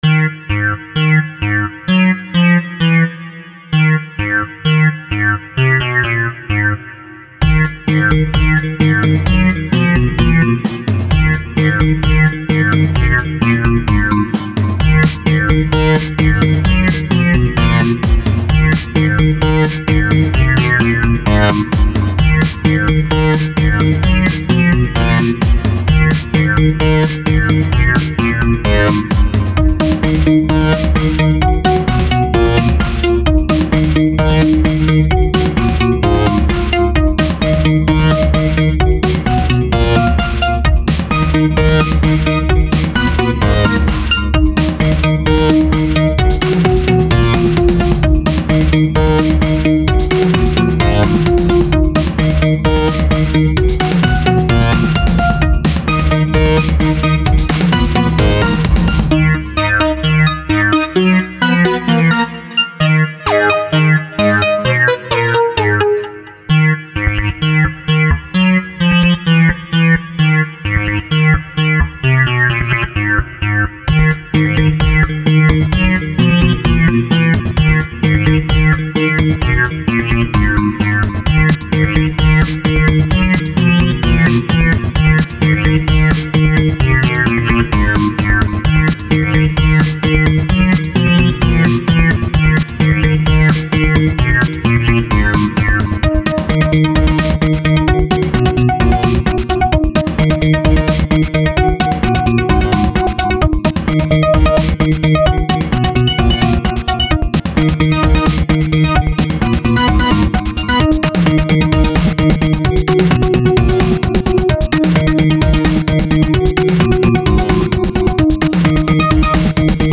I really like the variety of instruments in this.